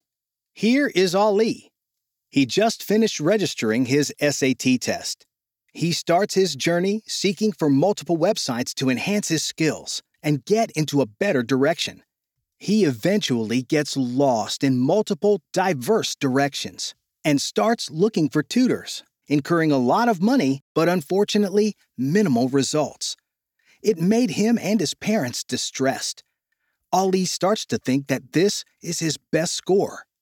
1012American_male_voice.mp3